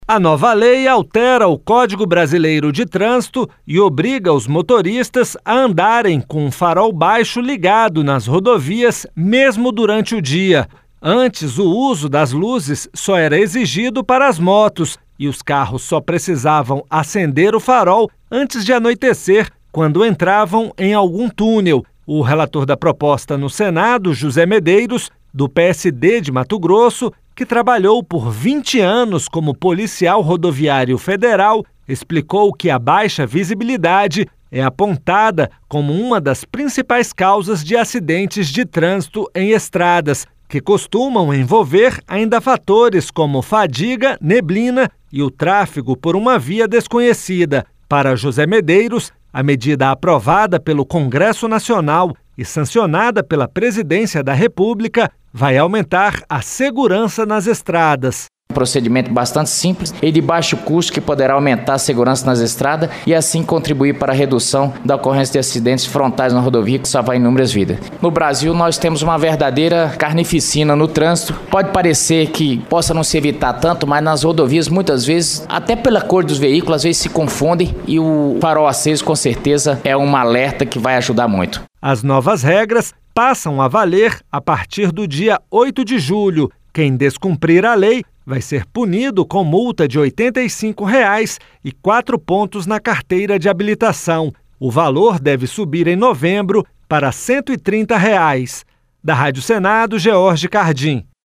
O áudio é da Rádio Senado.